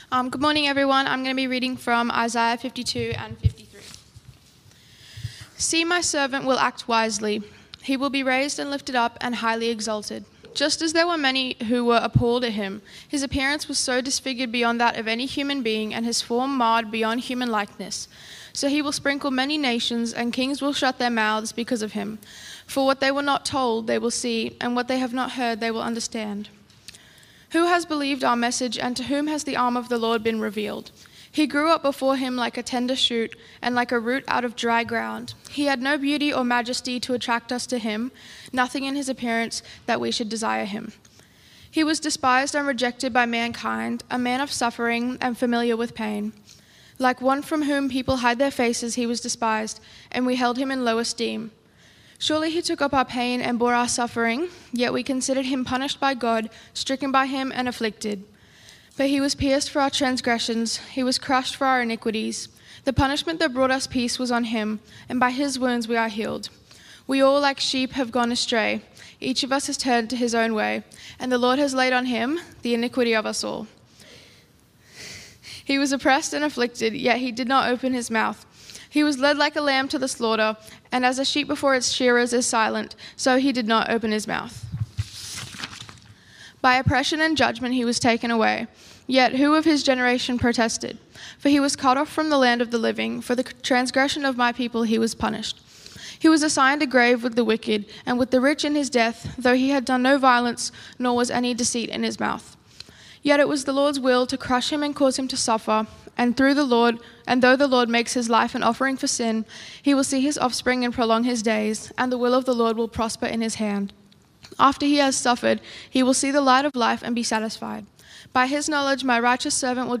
PennoBaps Sermons
Talks from Pennant Hills Baptist